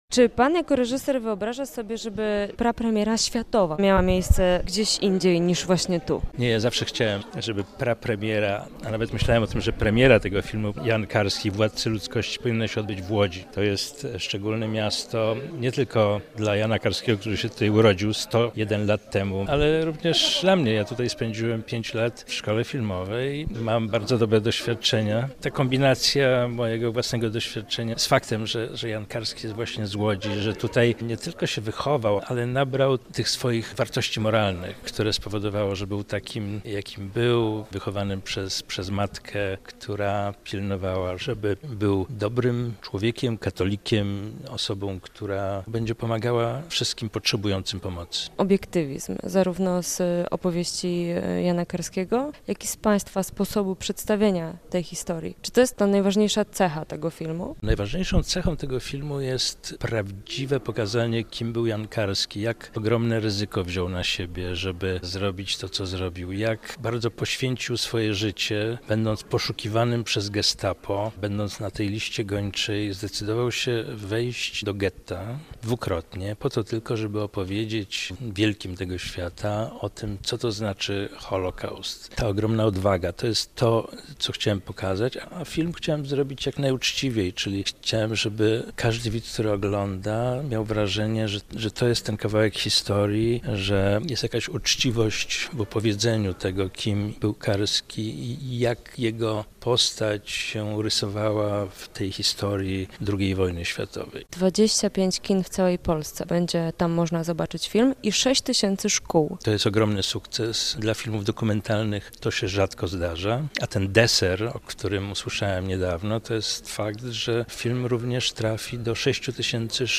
To polsko-amerykańska produkcja łącząca w sobie materiały archiwalne i elementy animacji. Posłuchaj rozmowy